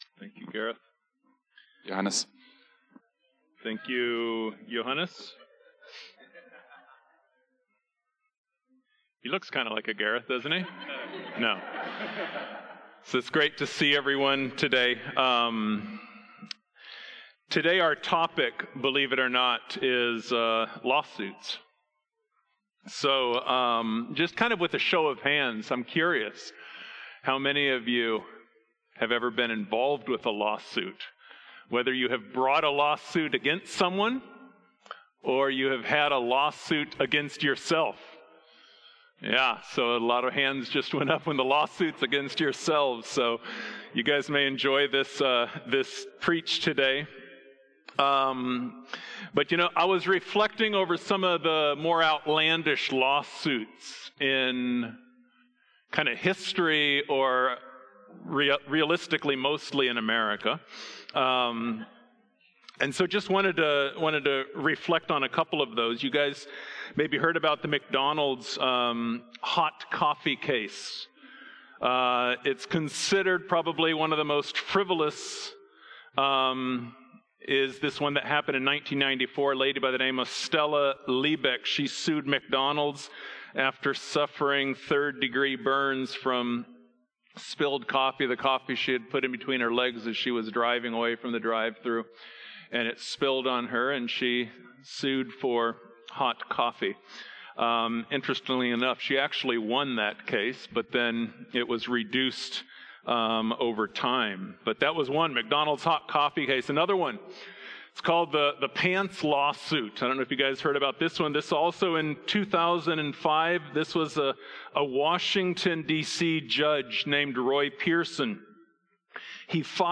A message from the series "Gospel Life."